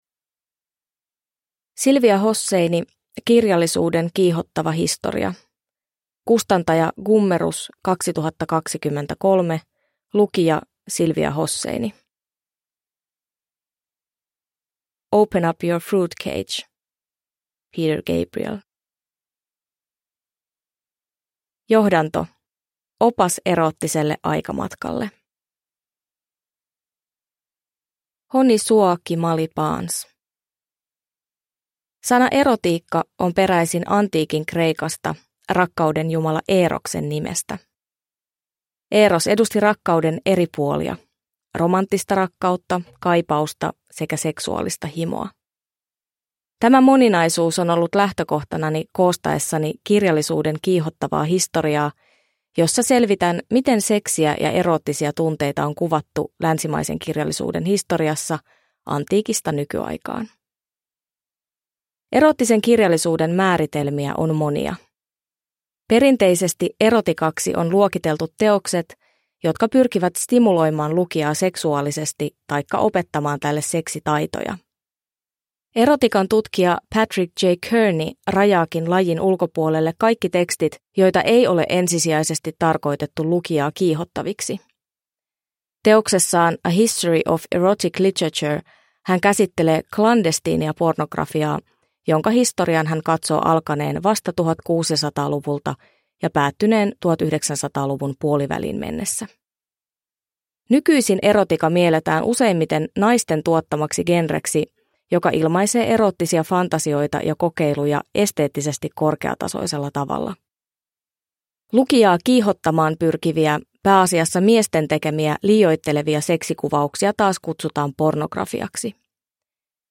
Kirjallisuuden kiihottava historia – Ljudbok – Digibok
Kirjallisuuden kiihottava historia – Ljudbok